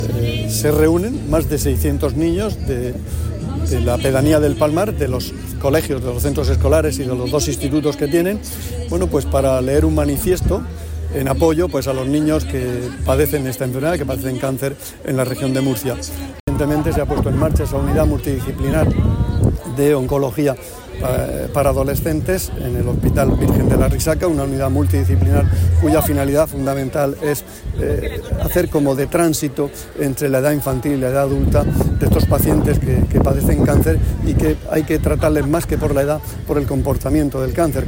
Declaraciones del consejero de Salud, Juan José Pedreño, sobre el acto celebrado en El Palmar por el Día Internacional del Cáncer Infantil.